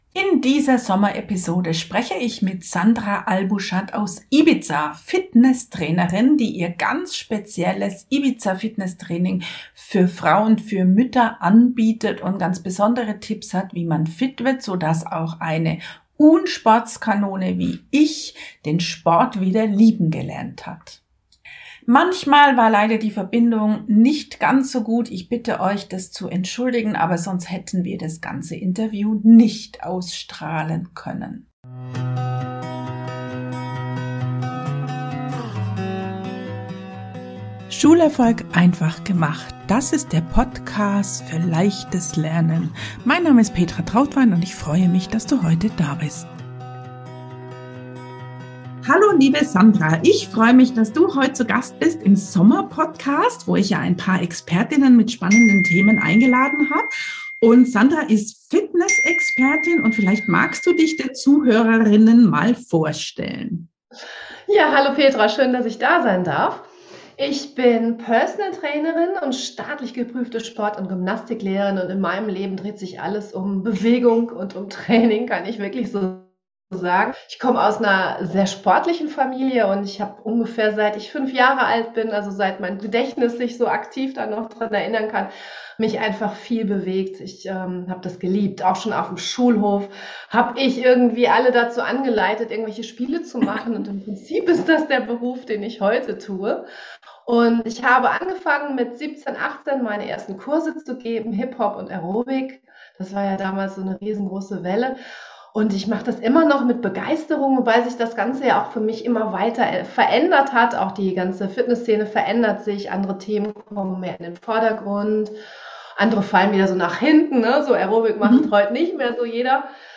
Sommer-Interview